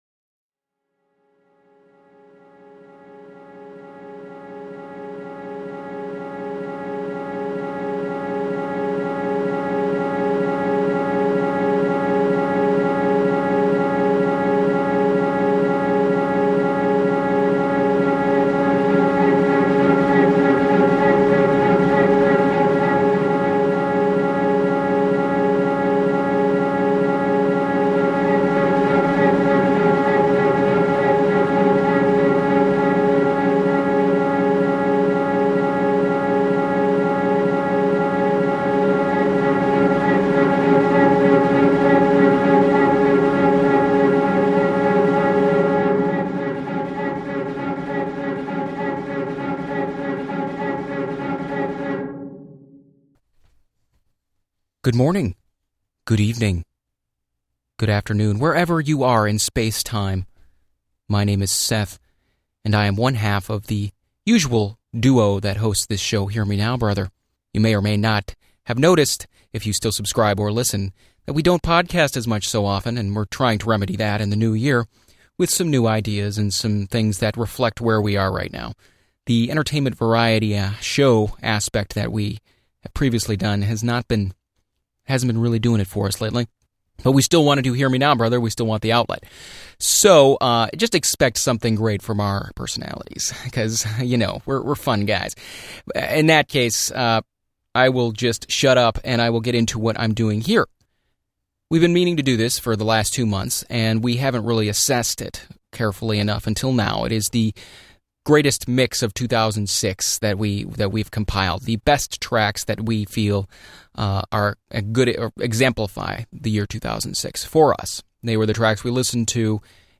A mixtape for you.